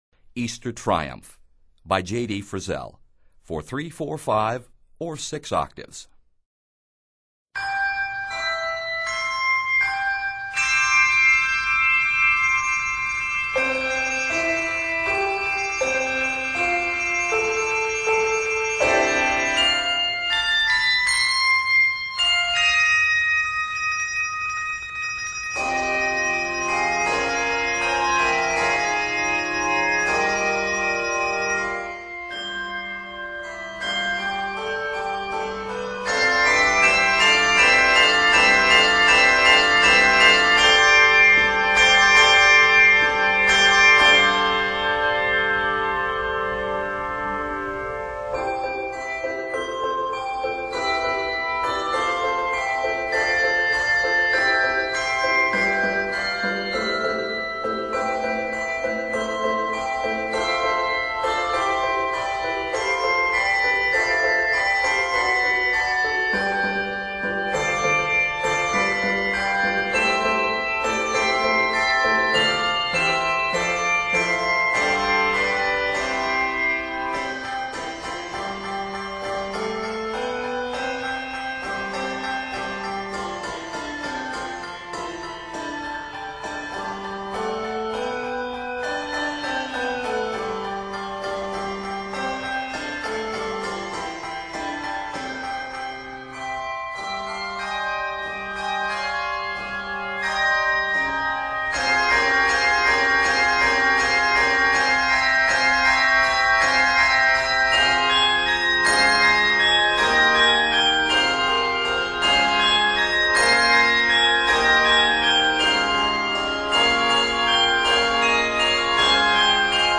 is scored in C Major and f minor.